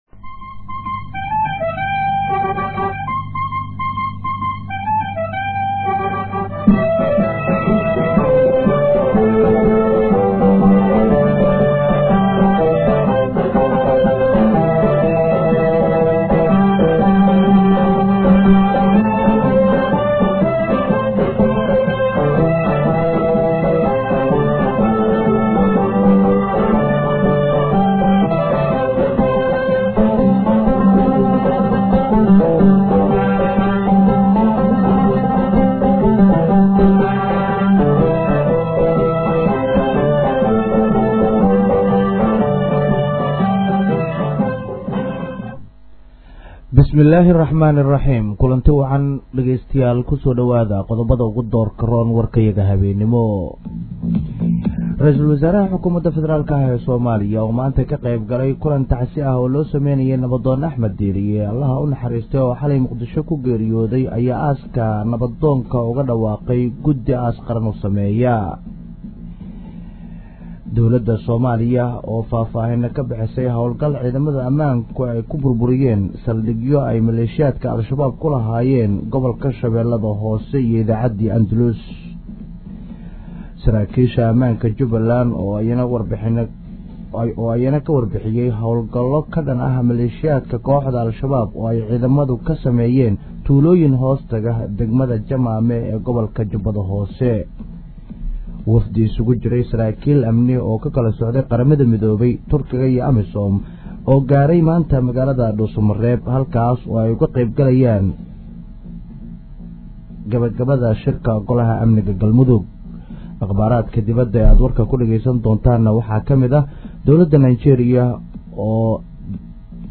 Dhageyso warka habeen ee Radio Muqdisho